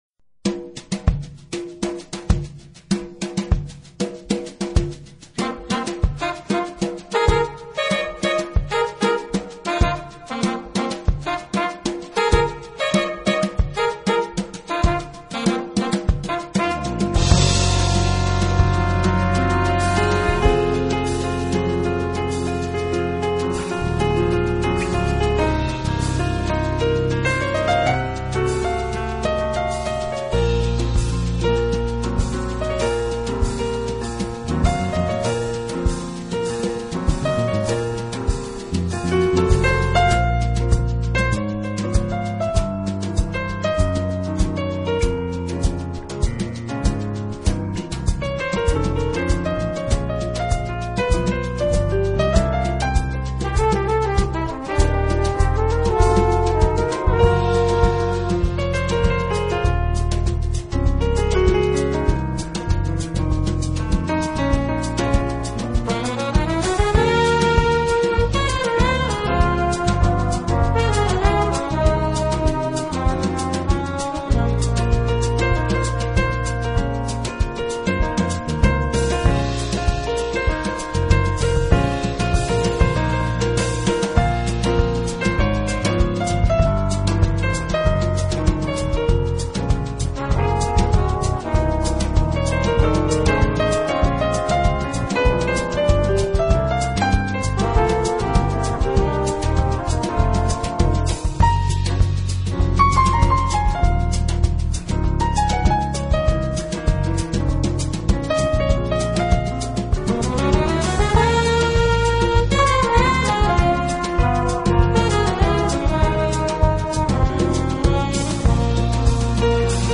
专辑类型：Jazz